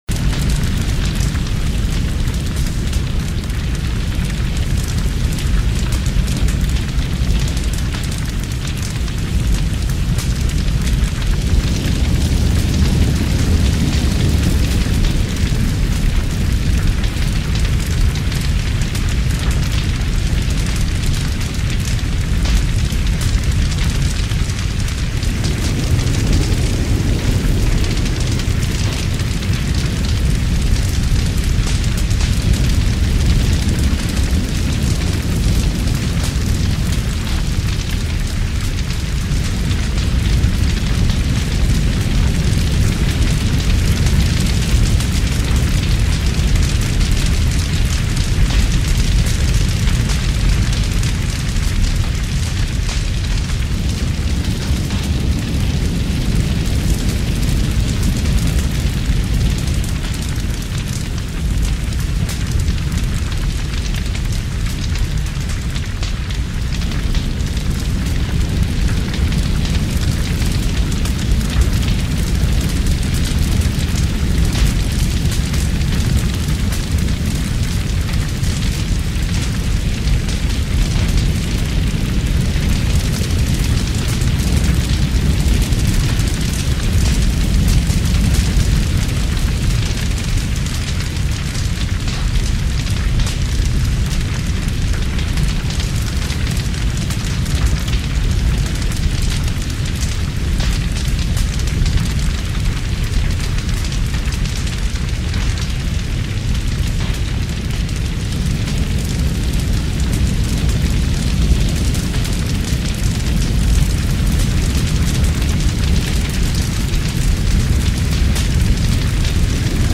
Звук оповещения с тревожным сигналом от МЧС